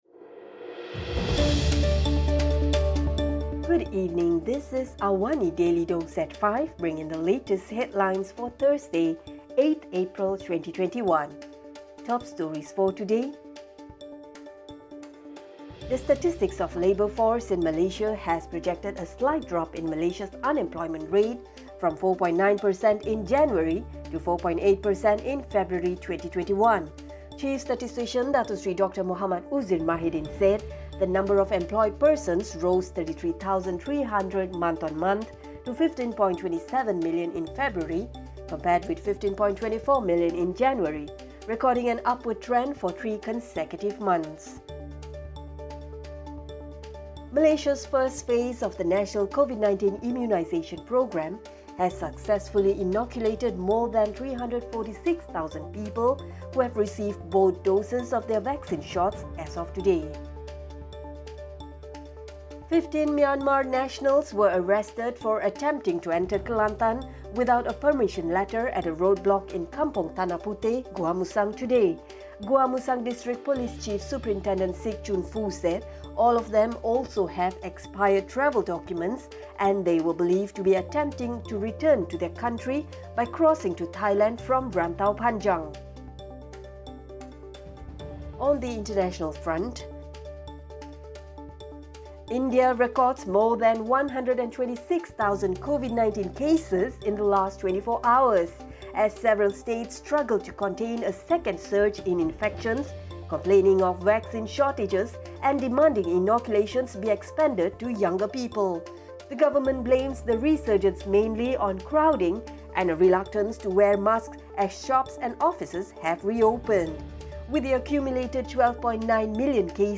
Also, India reported a record-high 126,789 new COVID-19 cases, health ministry data showed on Thursday, with much of the country struggling to contain a second surge in coronavirus infections. Listen to the top stories of the day, reporting from Astro AWANI newsroom — all in 3-minutes.